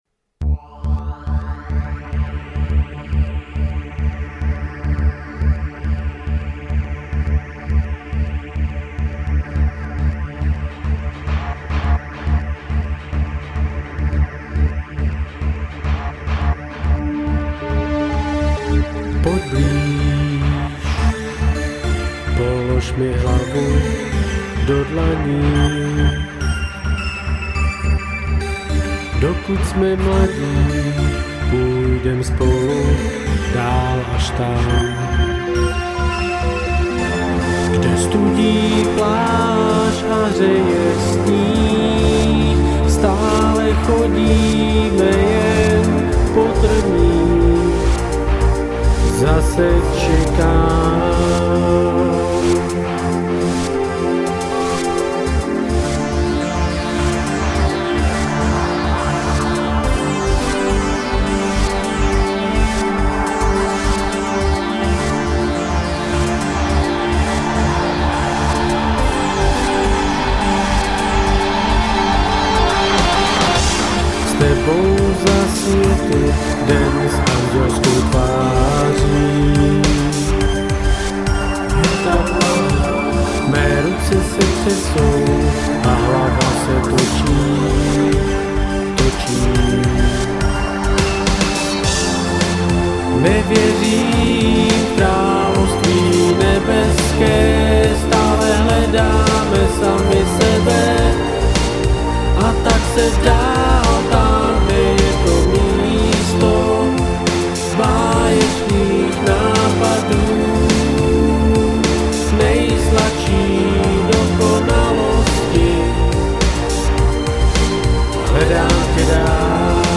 Žánr: Electro/Dance
místy zase spíš zklidňující.